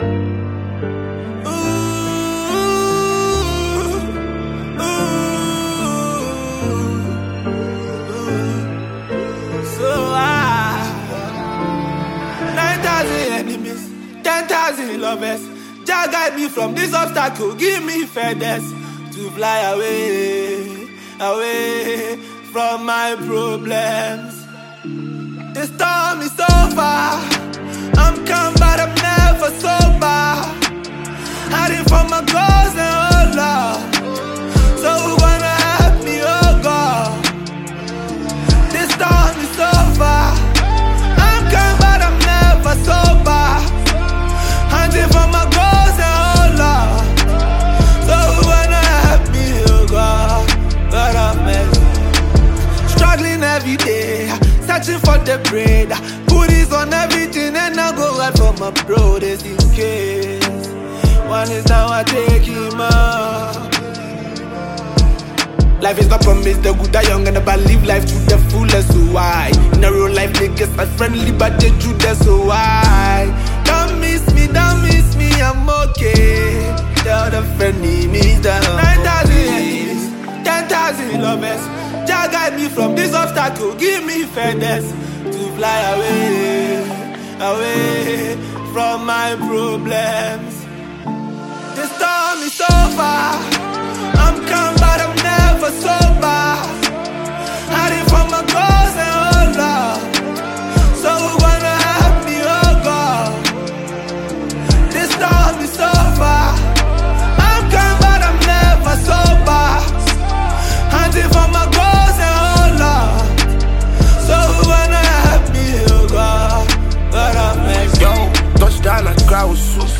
GHANA MUSIC
a top Ghanaian rapper and songwriter